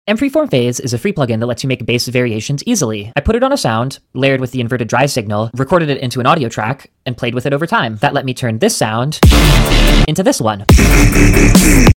BASS VARIATIONS MADE EASY 🎛 sound effects free download
Using the FREE mFreeformPhase plugin, I crafted dubstep-inspired basslines in seconds.